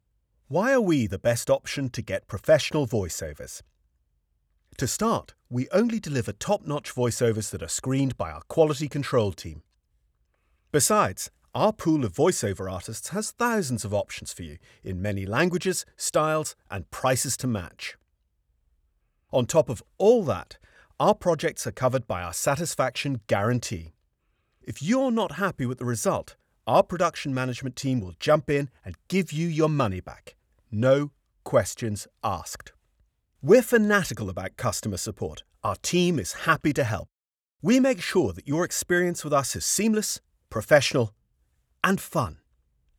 1. There are undesirable mouth noises in your recording that distract from the quality of the performance.
2. The phrases edits sound unnatural and abrupt, please use slow fades to help the deliverable sound more natural.
You have a really lovely voice!
The P from help sounds like it's missed off.
With regard to what has been done to the audio, I have applied a High Pass Filter (40Hz and 24dB/Oct) and Normalised to -3, that's it.
When I'm listening to the audio there does sound like there is white noise present throughout your recording.
As for mouth noises, I can hear them.
Again at the beginning, when you finish the first line it sounds like there is a mixture of a swallowing sound/breathing, and heard again just before the second. Some mouth movements can be heard later on when reading: 'We're fanatical about customer support, our team is happy to help'.